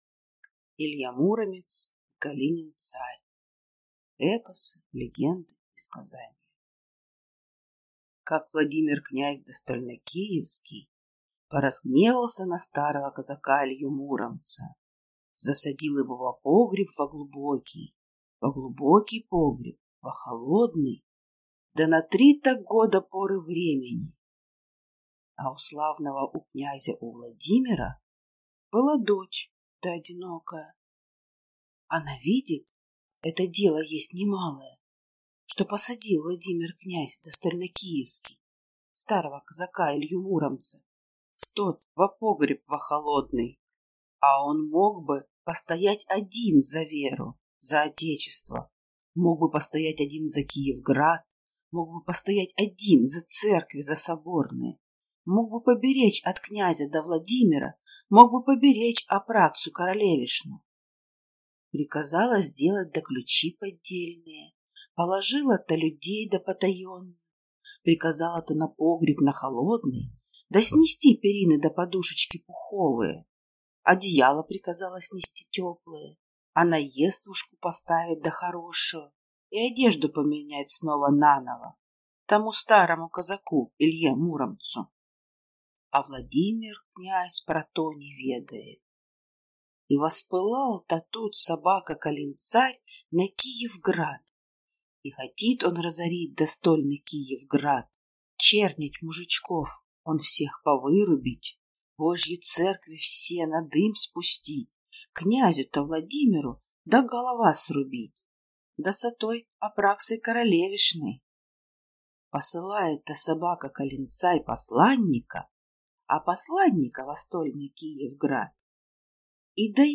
Аудиокнига Илья Муромец и Калин-царь | Библиотека аудиокниг